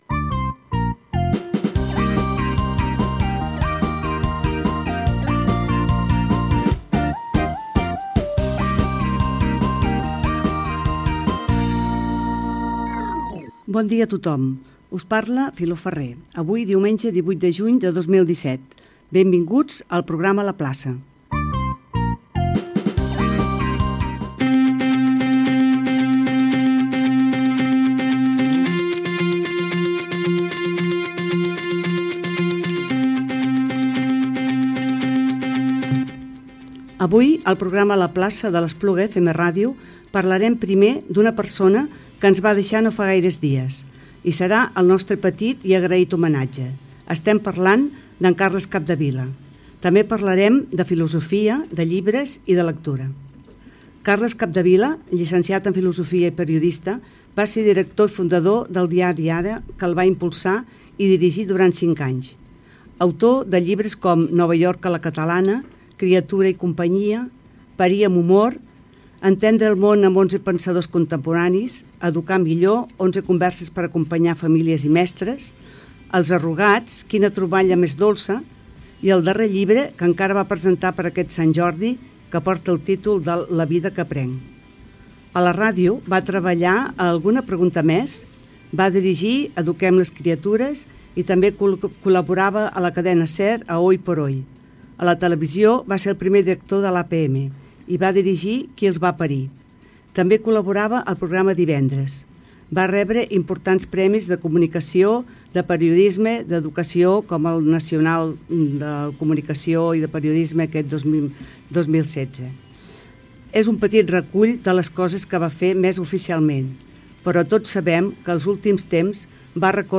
Sintonia, data, benvinguda, sumari i comentari sobre la mort del periodista Carles Capdevila.
Entreteniment